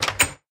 door_open.mp3